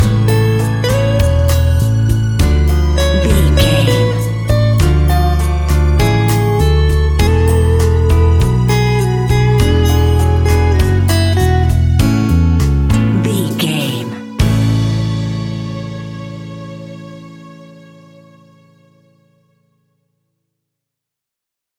An exotic and colorful piece of Espanic and Latin music.
Ionian/Major
Slow
romantic
maracas
percussion spanish guitar
latin guitar